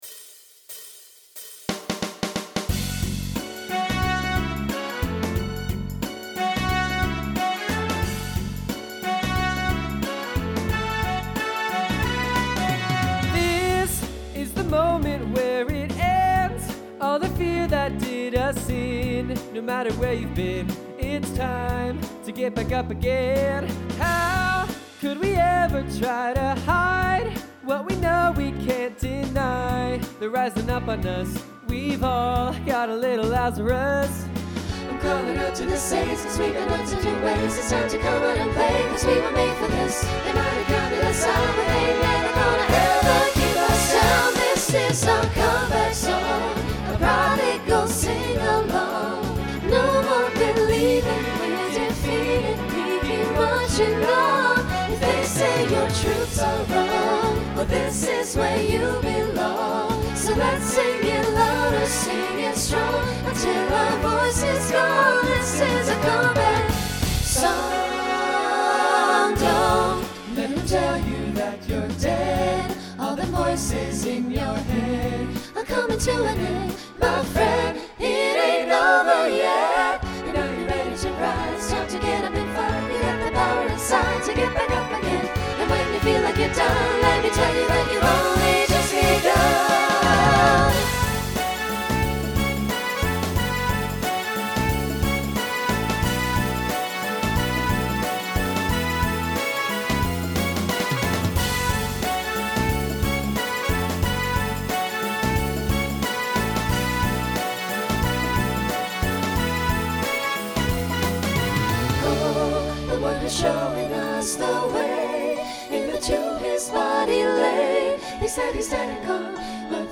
Voicing SATB Instrumental combo Genre Rock
Mid-tempo